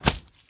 sounds / mob / slime / big2.ogg